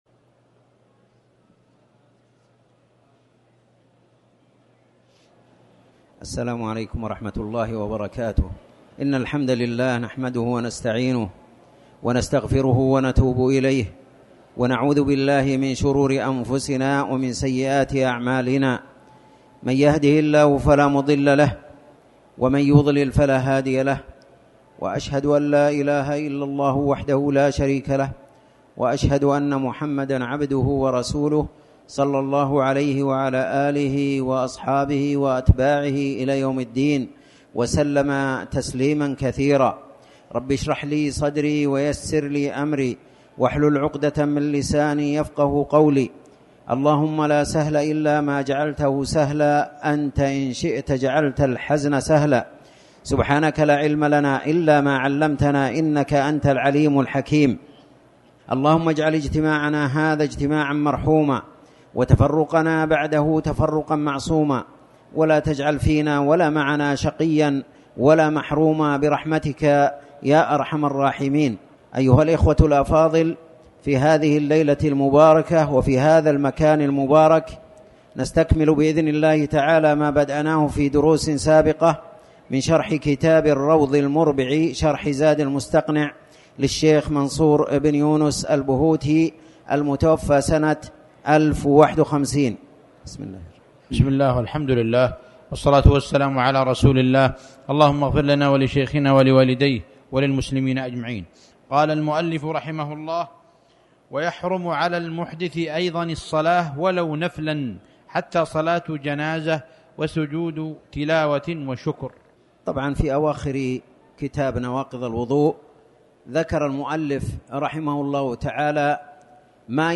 تاريخ النشر ١١ ربيع الأول ١٤٤٠ هـ المكان: المسجد الحرام الشيخ